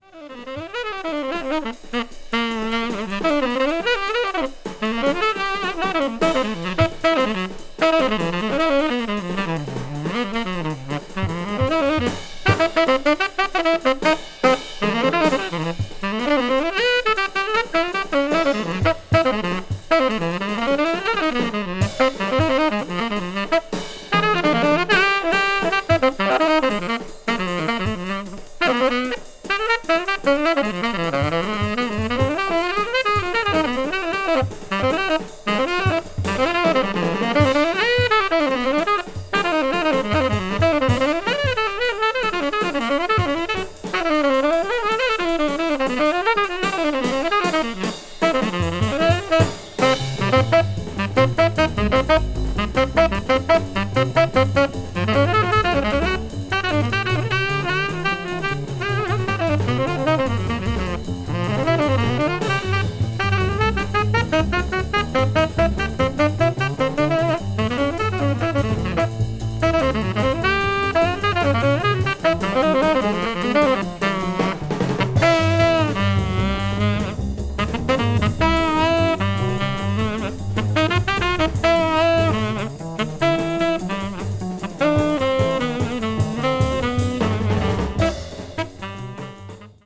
bossa jazz   hard bop   modern jazz   post bop